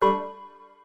tbd-station-14/Resources/Audio/Effects/error.ogg
error.ogg